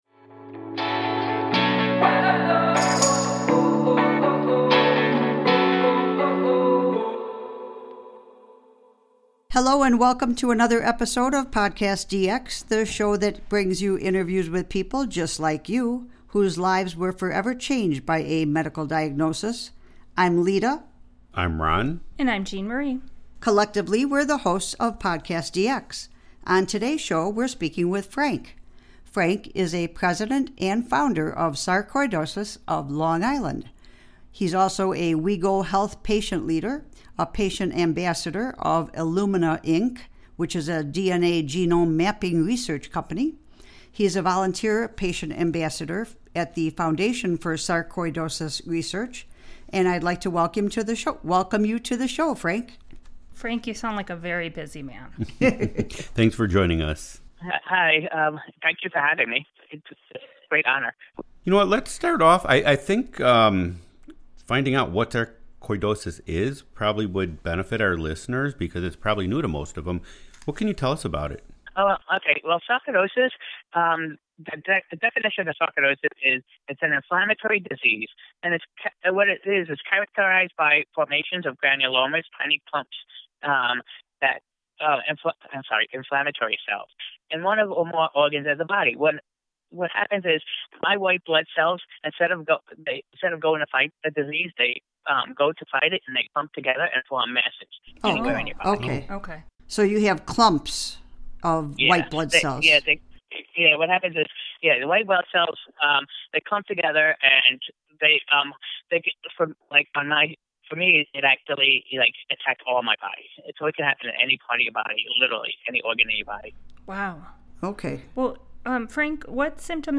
Sarcoidosis (pronounced SAR-COY-DOE-SIS) is an inflammatory disease characterized by the formation of granulomas—tiny clumps of inflammatory cells—in one or more organs of the body. It is a chronic, organ scarring disease that has no known cure.